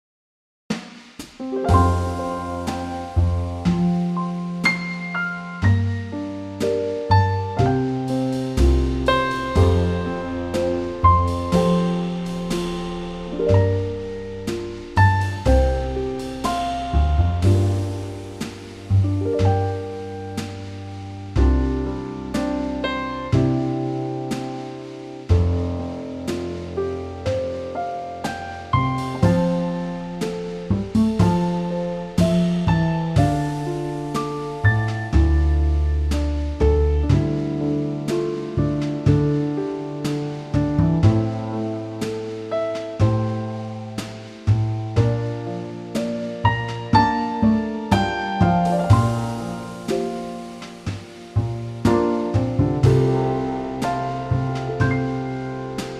4 bar intro, vocal through all the way
key - F - vocal range - C to D
Lovely song in a Trio arrangement